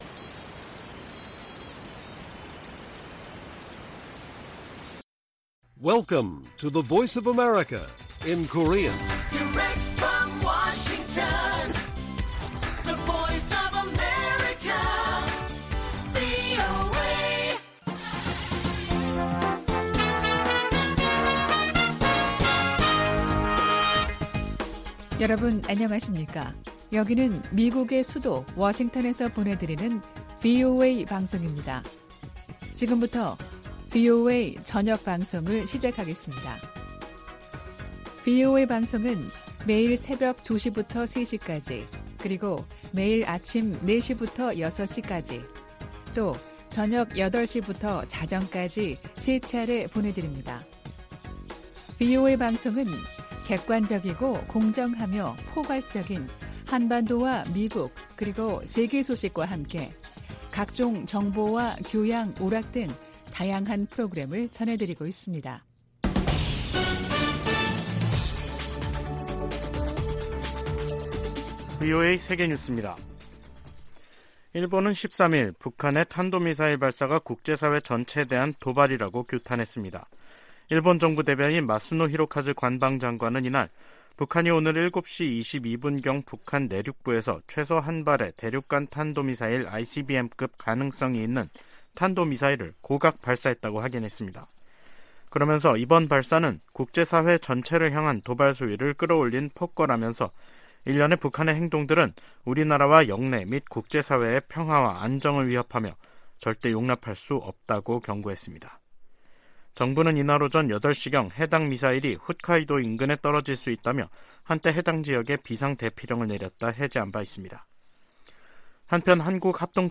VOA 한국어 간판 뉴스 프로그램 '뉴스 투데이', 2023년 4월 13일 1부 방송입니다. 북한이 중거리급 이상의 탄도 미사일을 동해쪽으로 발사했습니다.